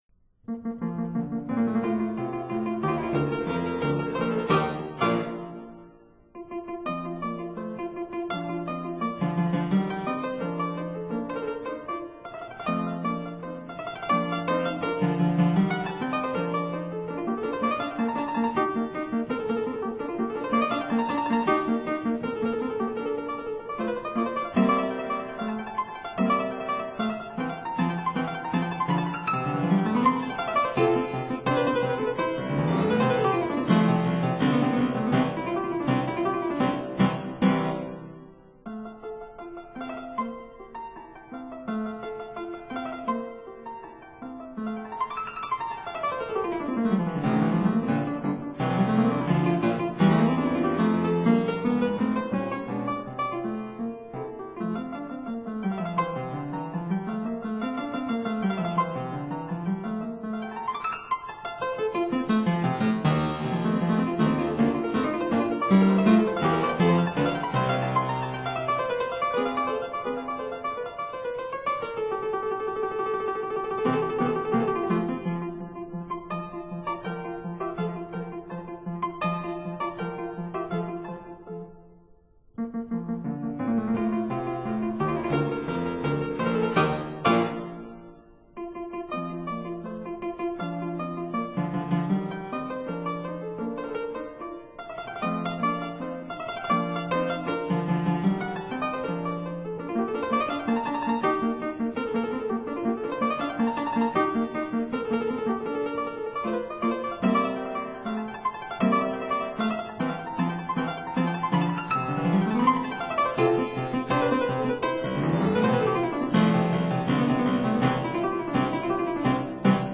Fortepiano
(Period Instruments)